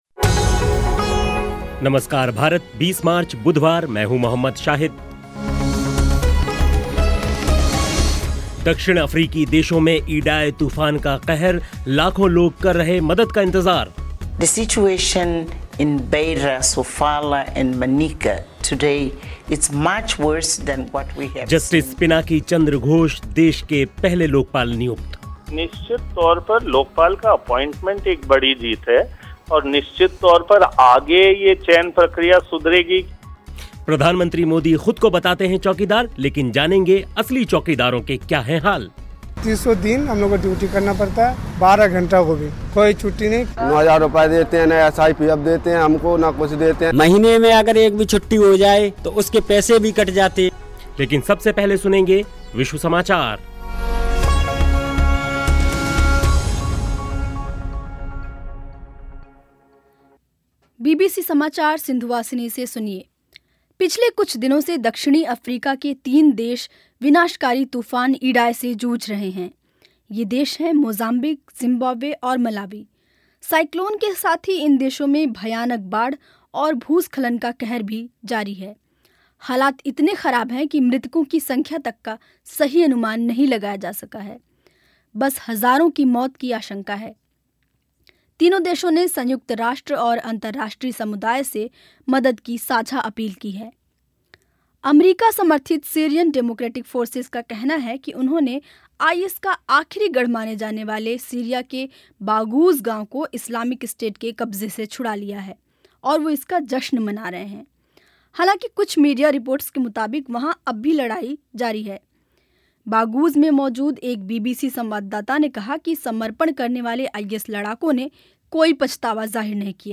प्रियंका गांधी के वाराणसी दौरे पर भी होगी रिपोर्ट लेकिन सबसे पहले विश्व समाचार सुनिए.